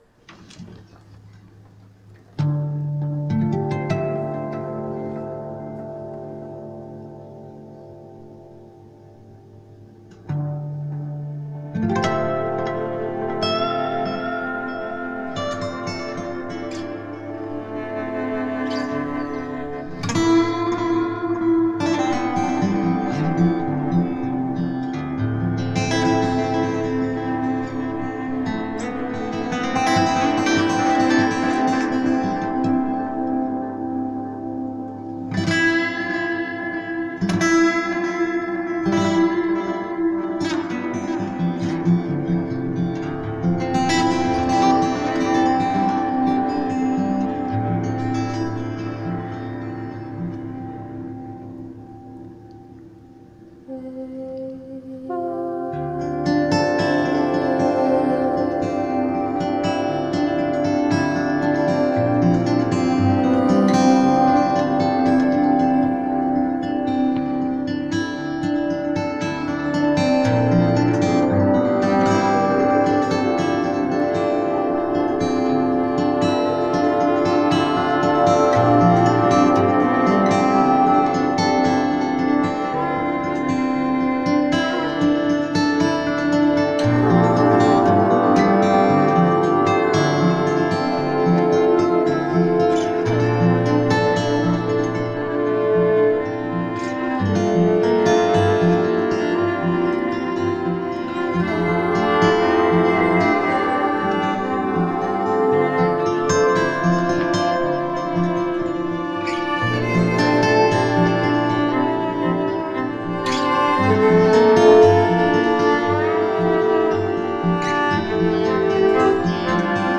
DELAY (rough mix). Guitar
Viola, vocals and audio mixing